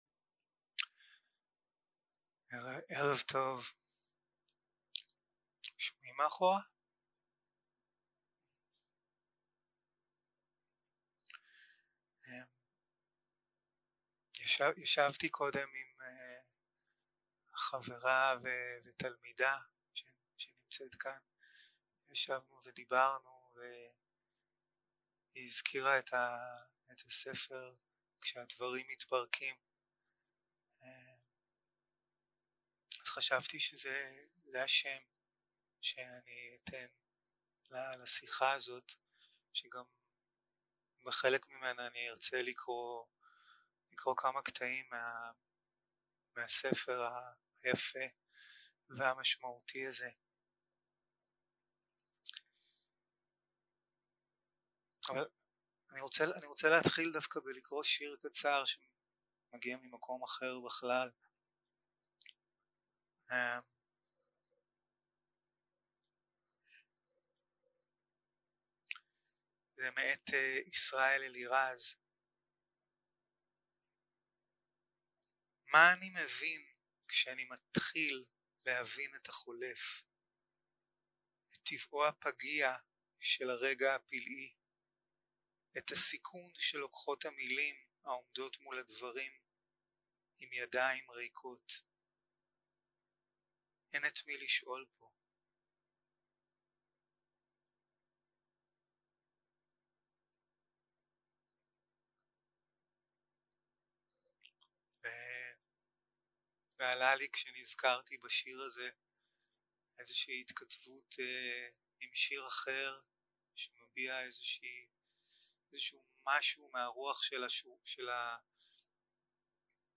סוג ההקלטה: שיחות דהרמה